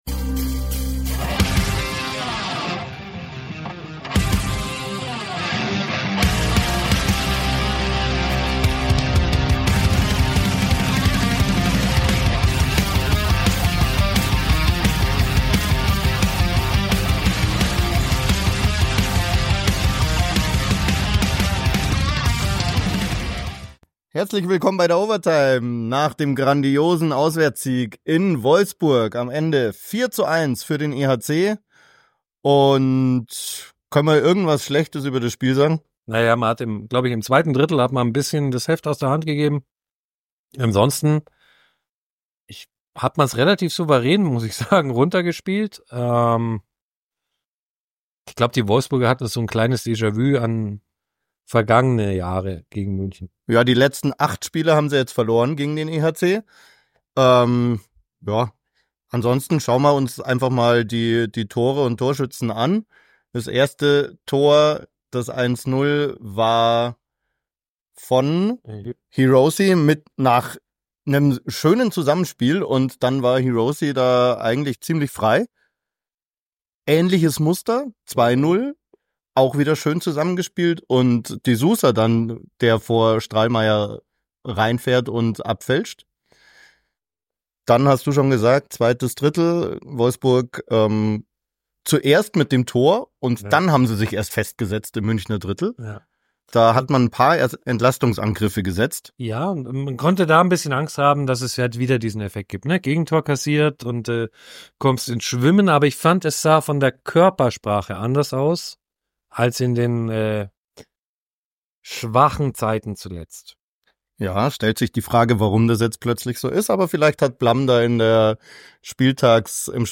Spielzusammenfassung und Stimmen